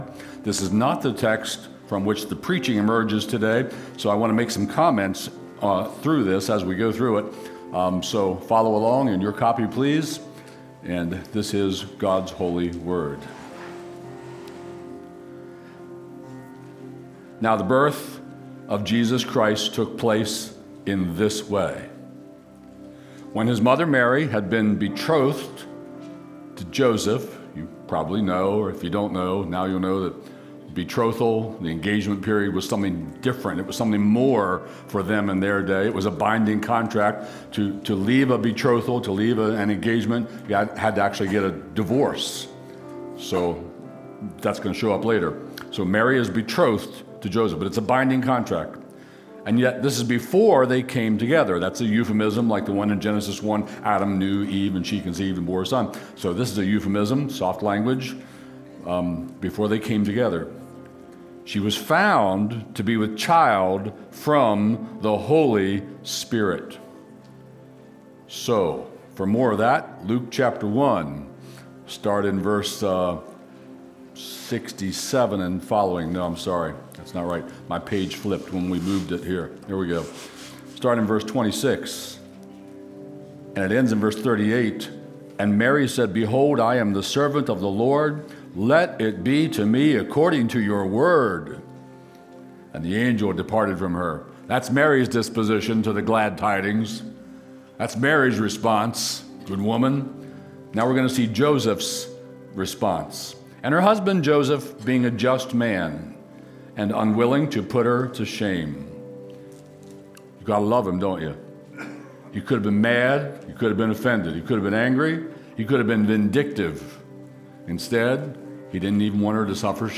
Stream Sermons from Cornerstone Harford County